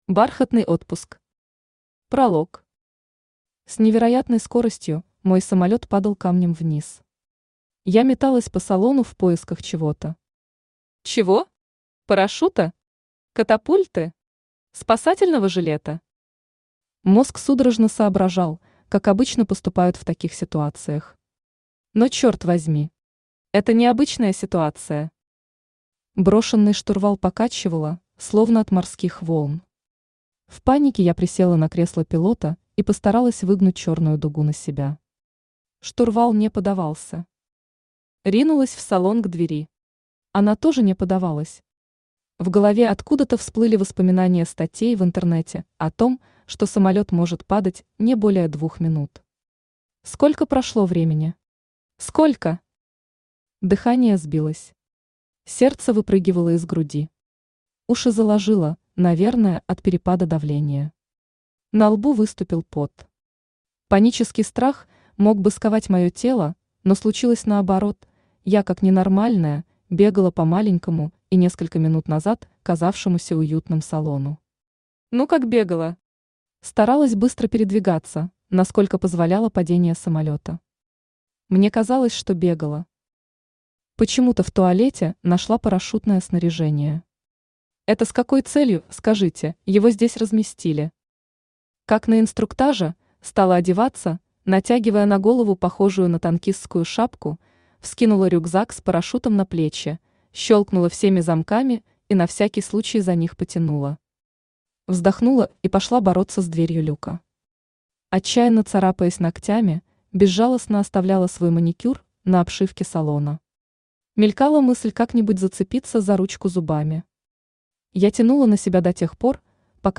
Aудиокнига Бархатный отпуск Автор Ася Юрьевна Вакина Читает аудиокнигу Авточтец ЛитРес.